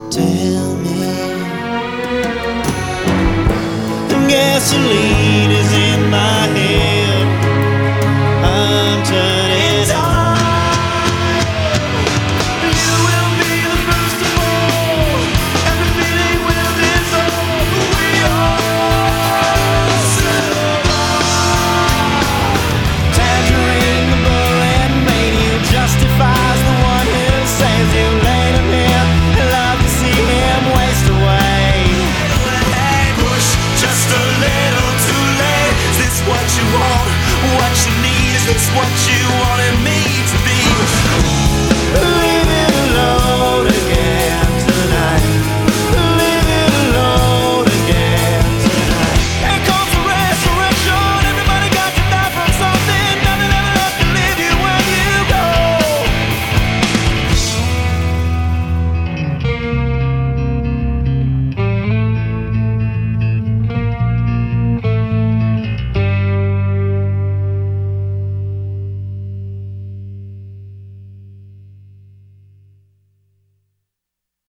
rock alternatif canadien
MONTAGE AUDIO